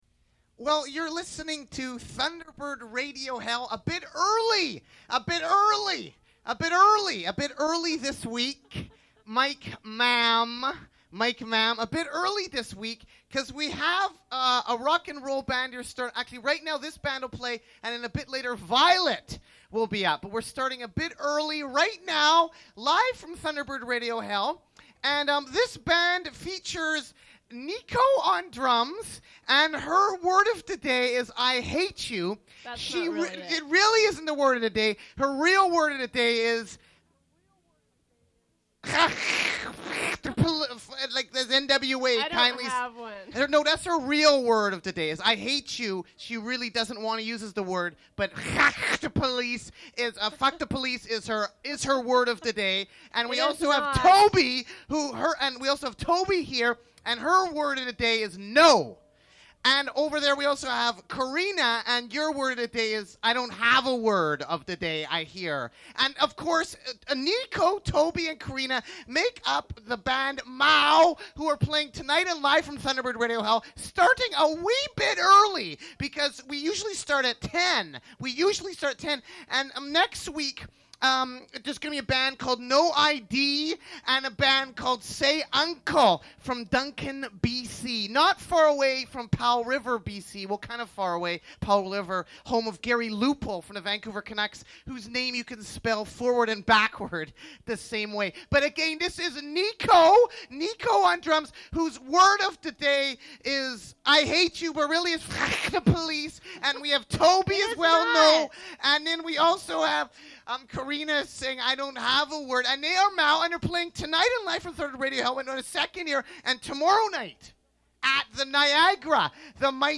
Recording of a live performance
Vancouver-based musical group
including introductions by Nardwuar the Human Serviette.